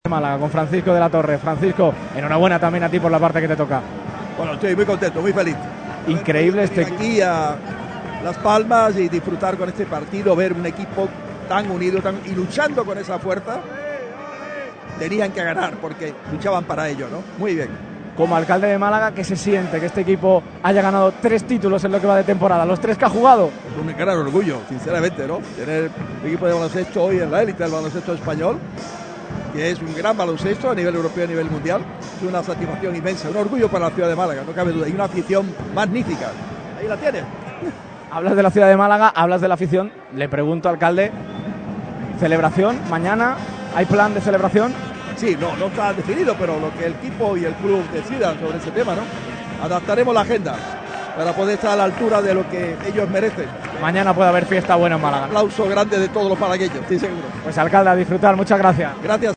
El presidente de Unicaja Baloncesto, Antonio Jesús López Nieto, y el alcalde de Málaga, Francisco De La Torre, han comparecido ante el micrófono de Radio MARCA Málaga en la celebración postpartido tars la consecución del cuadro de Los Guindos de su tercera Copa del Rey en Gran Canaria.